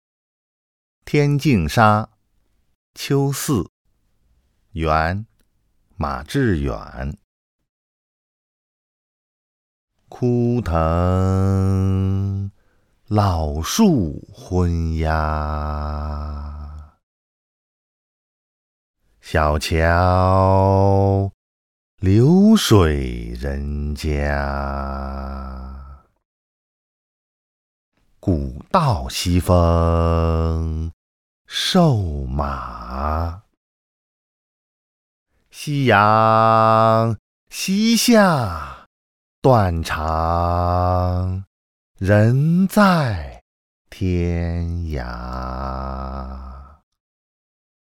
［元］马致远《天净沙·秋思》（读诵）